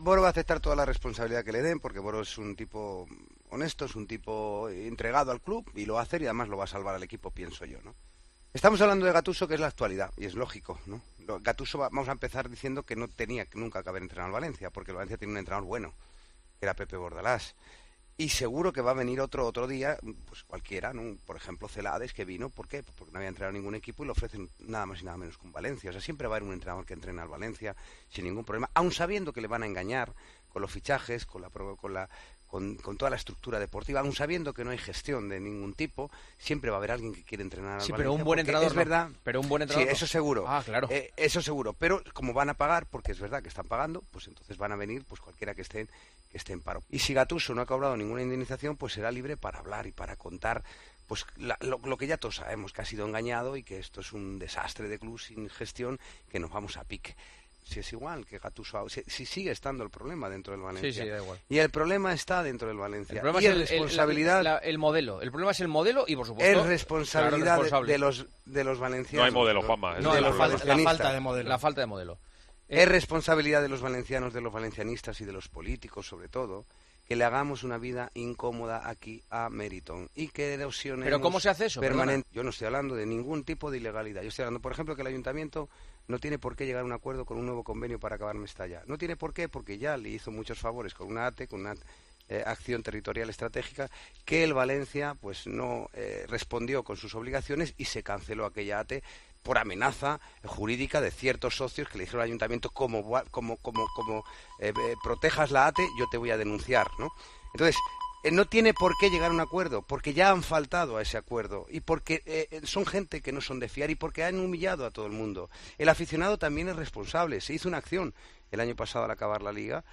La leyenda del Valencia CF y comentarista COPE vuelve a dejar clara su opinión: "Gattuso nunca debió entrenar al Valencia CF porque ya había un buen entrenador"